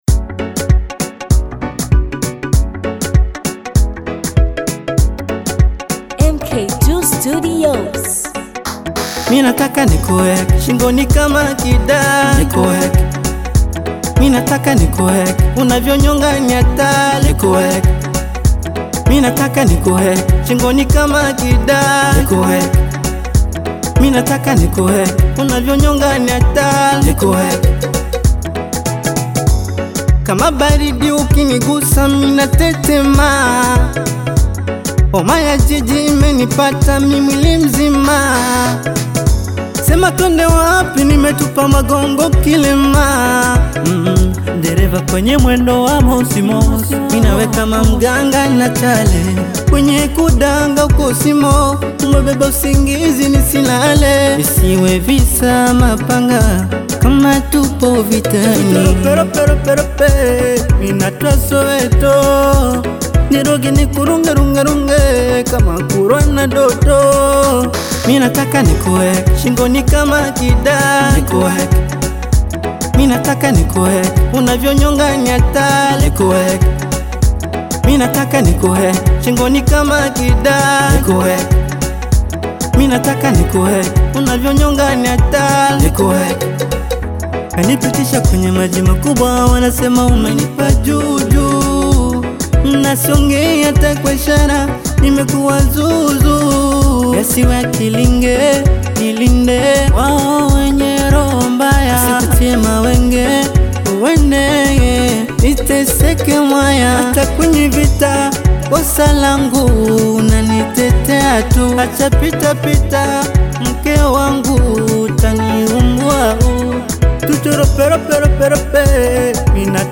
club banger track